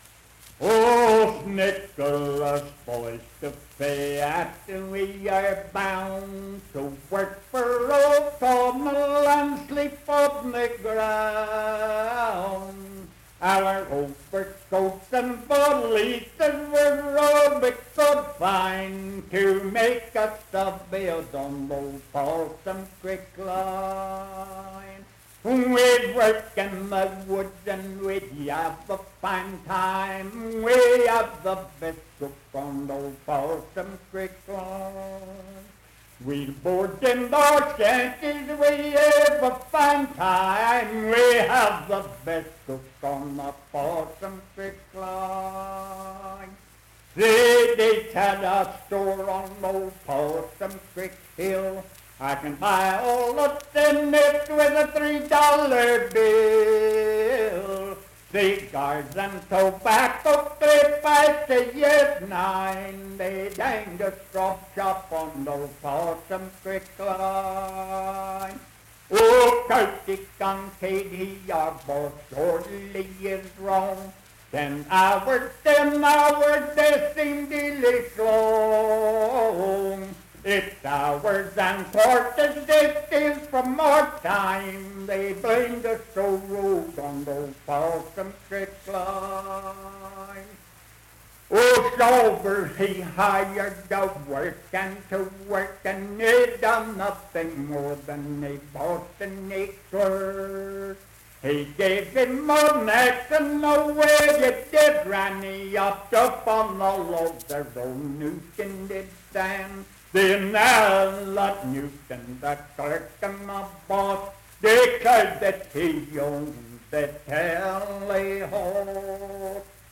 Unaccompanied vocal music performance
Verse-refrain 9d(4).
Voice (sung)